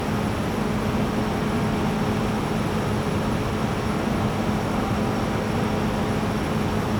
pgs/Assets/Audio/Sci-Fi Sounds/Mechanical/Engine 8 Loop.wav at master
Engine 8 Loop.wav